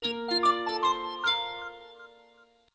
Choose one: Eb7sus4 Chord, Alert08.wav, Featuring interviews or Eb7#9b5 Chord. Alert08.wav